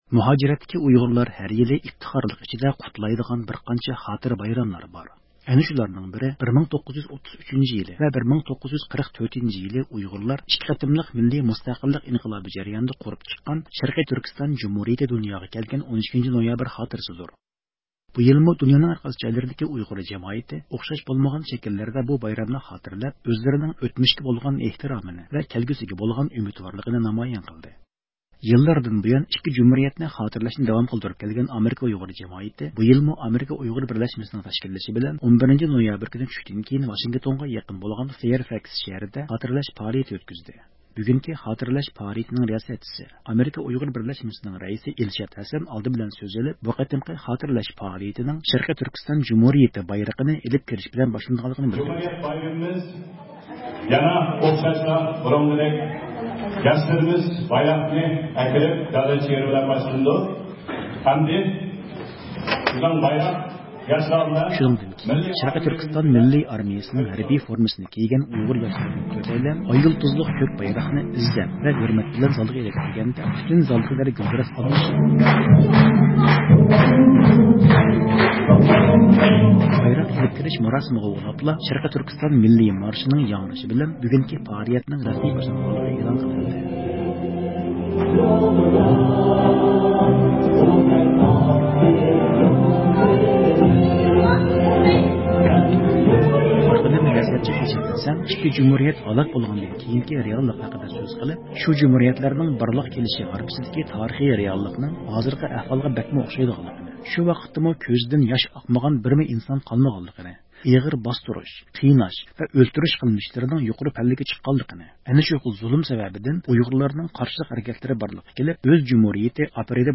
يىللاردىن بۇيان «ئىككى جۇمھۇرىيەت» نى خاتىرىلەشنى داۋام قىلدۇرۇپ كەلگەن ئامېرىكا ئۇيغۇر جامائىتى بۇ يىلمۇ ئامېرىكا ئۇيغۇر بىرلەشمىسىنىڭ تەشكىللىشى بىلەن 11-نويابىر كۈنى چۈشتىن كېيىن ۋاشىنگتونغا يېقىن فايرفاكس شەھىرىدە خاتىرىلەش پائالىيىتى ئۆتكۈزدى.